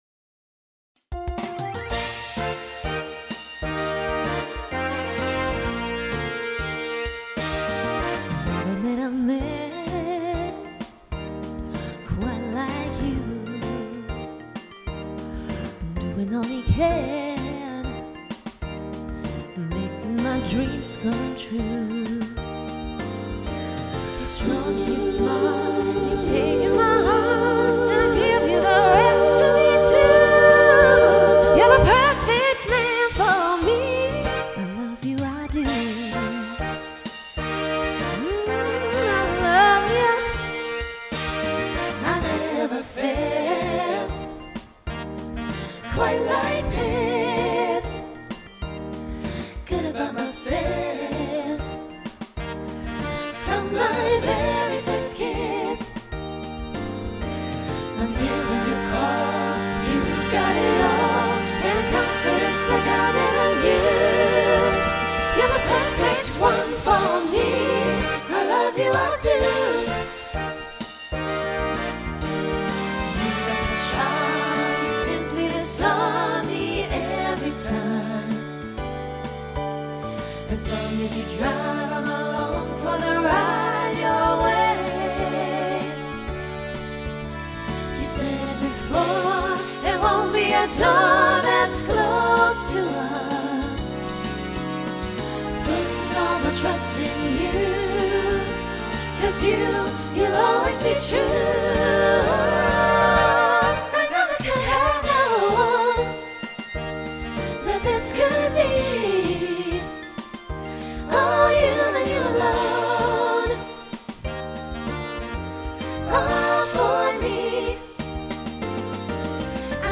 Mid-tempo